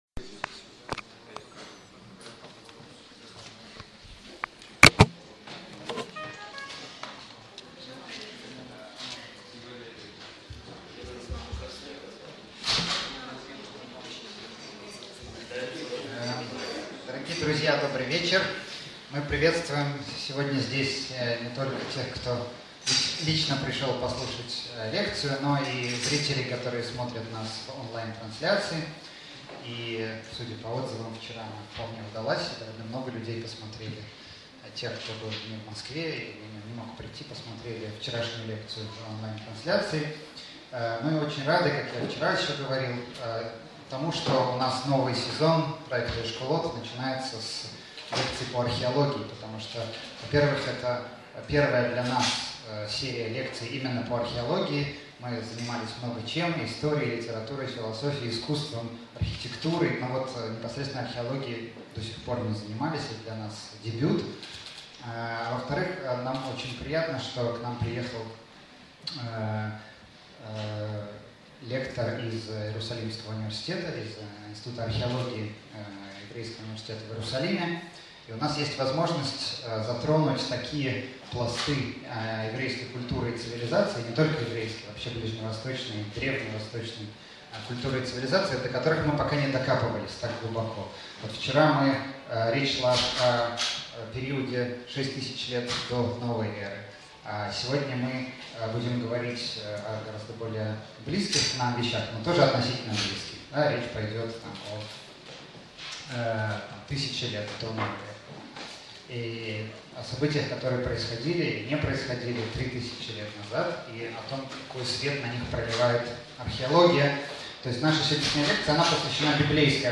Аудиокнига Царство Давида в свете археологии | Библиотека аудиокниг